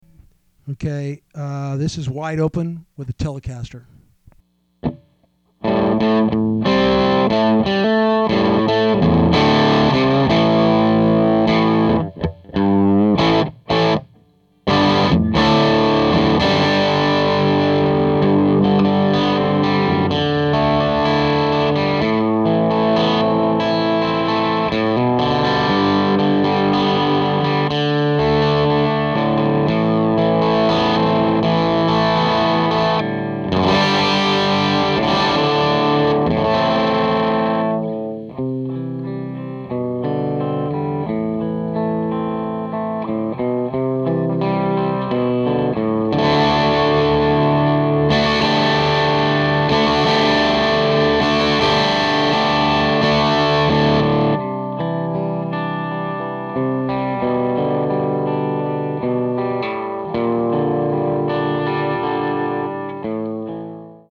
Wide Open no pedals or enhancements
The CarpAmps 1030 is connected to a 12" Celestion Alnico Gold and is being recorded with a sennheiser e609 mic.
The settings on the Amp are Wide Open.
wideopen.mp3